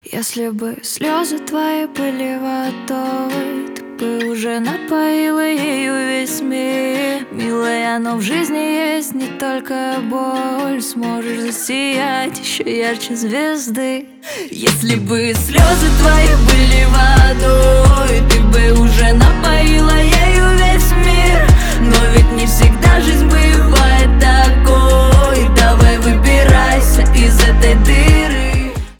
гитара , грустные , поп